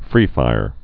(frēfīr)